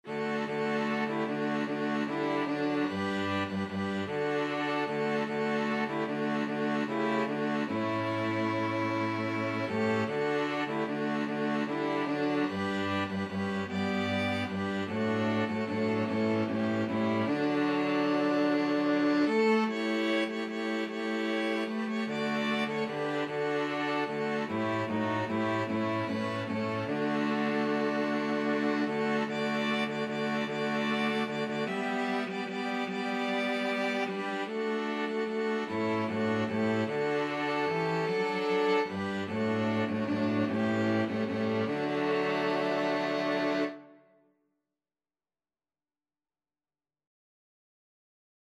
Score Key: D major (Sound